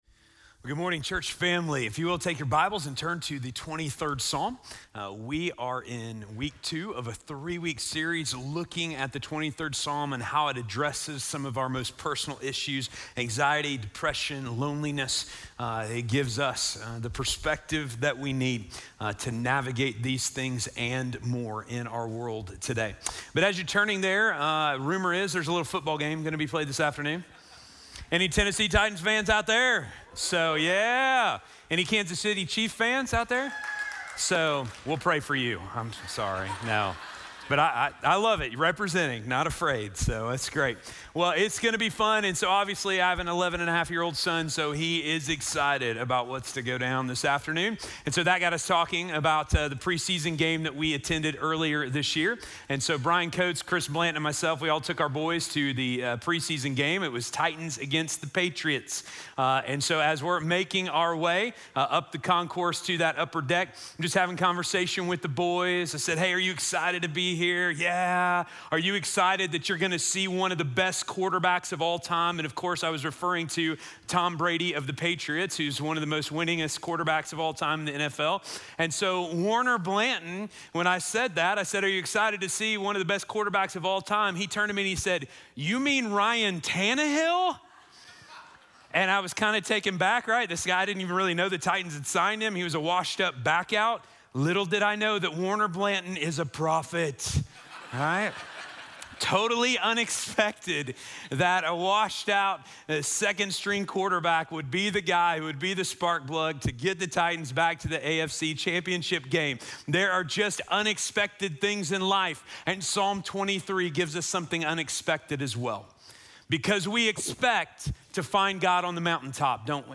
Dealing with Depression - Sermon - Station Hill